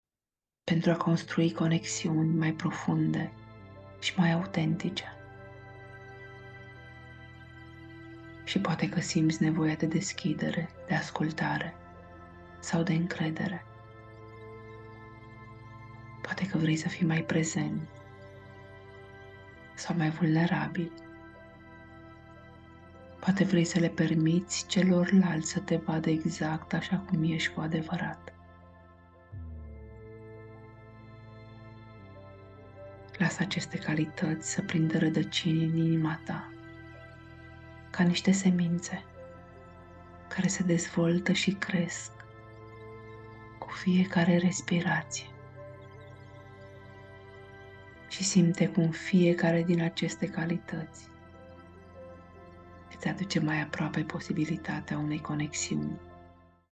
Meditatie audio Ghidata